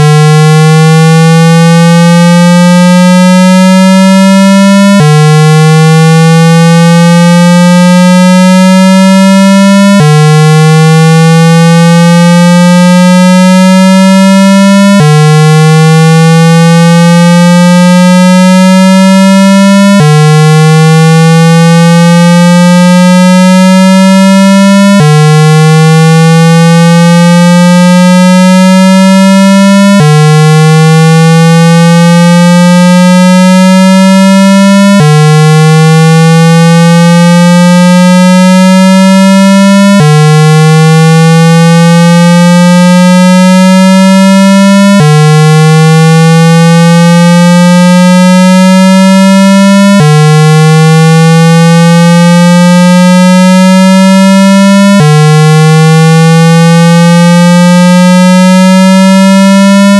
• Cách thực hiện: Phát âm thanh tần số cao (thường từ 165Hz đến 200Hz) trong 2-3 phút.
Sound for Speaker Cleaner 150-200Hz hoặc Sound for Speaker Cleaner 1-200Hz (lưu ý không được đeo tai nghe để nghe âm thanh).